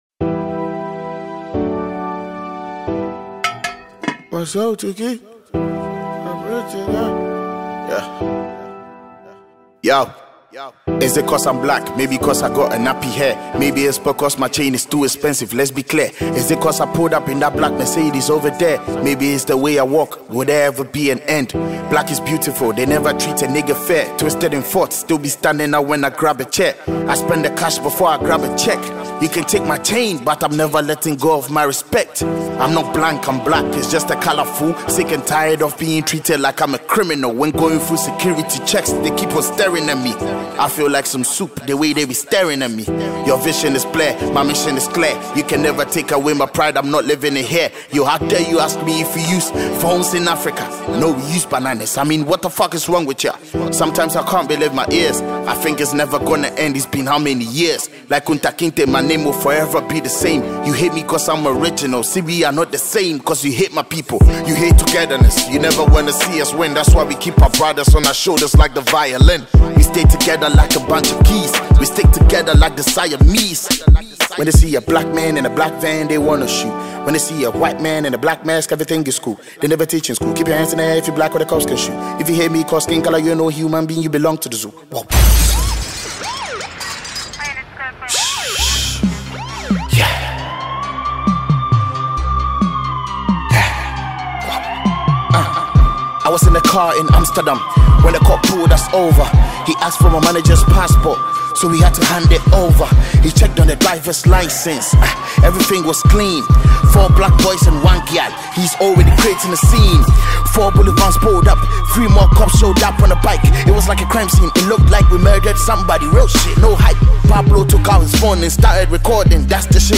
Ghanaian rapper
hip-hop